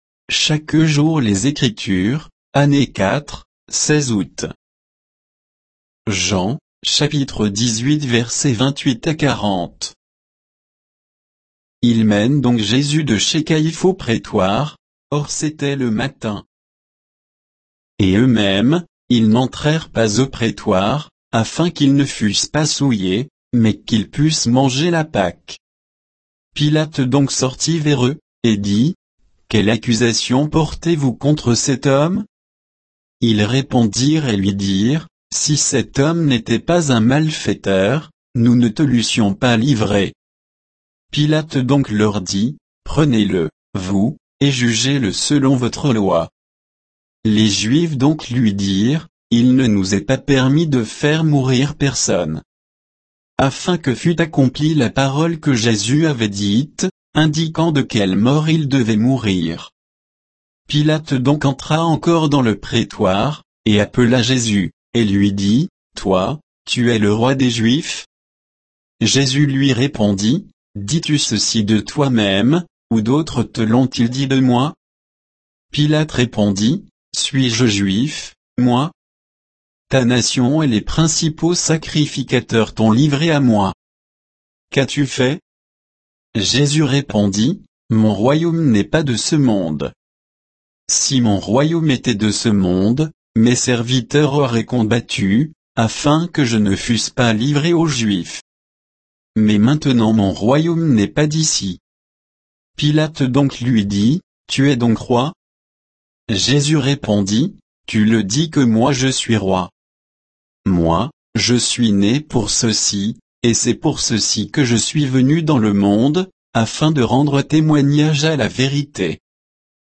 Méditation quoditienne de Chaque jour les Écritures sur Jean 18